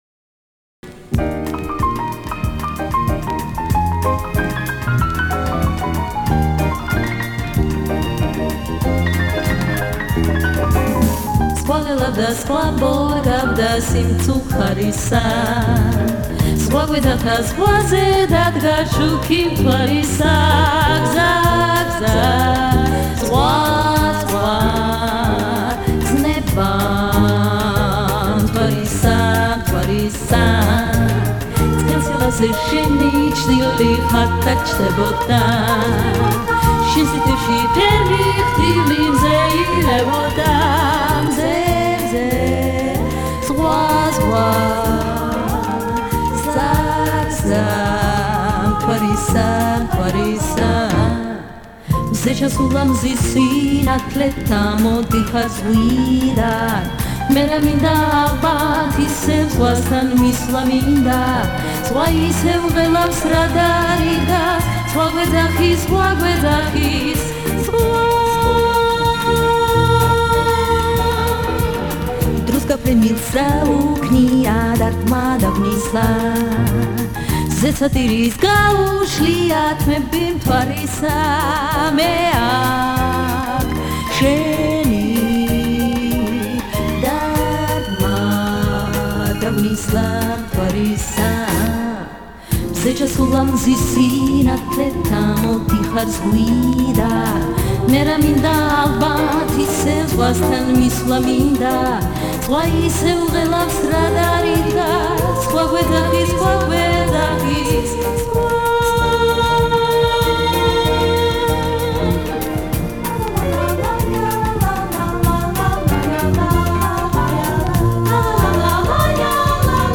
песня обрывается в конце